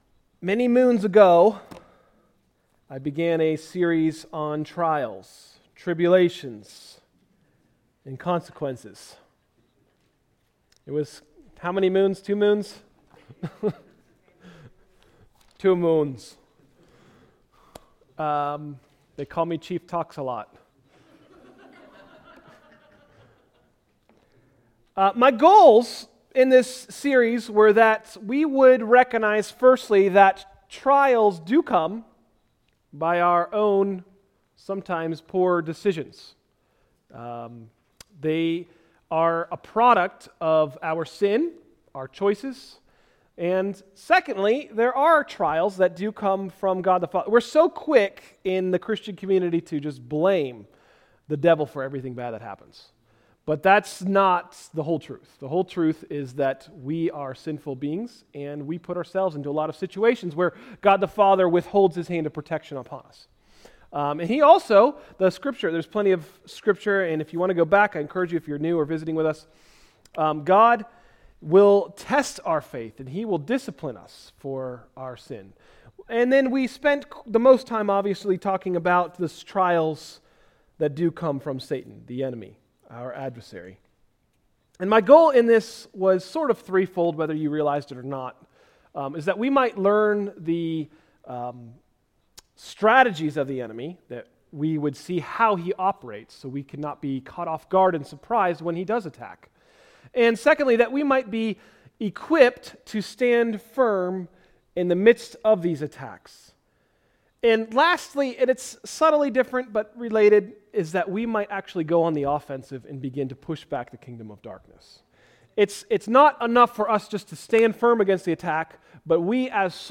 Sermons – Tried Stone Christian Center